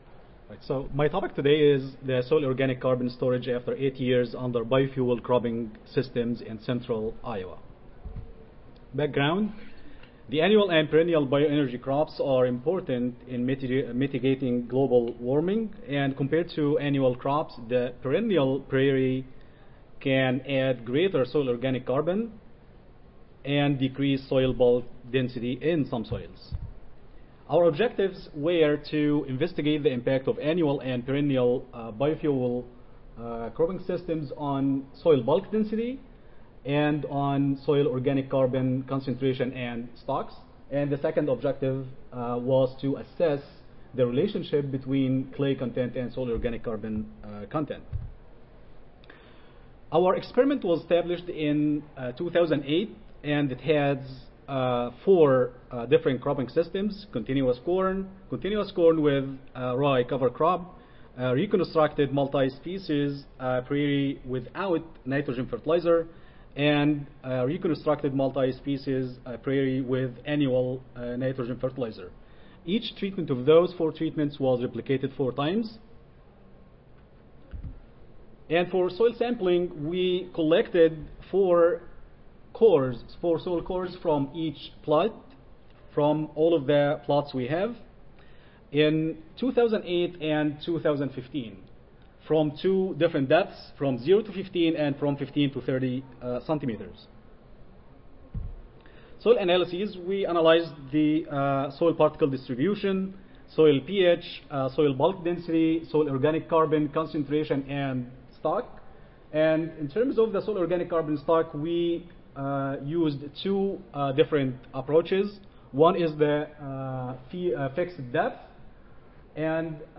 Iowa State University Audio File Recorded Presentation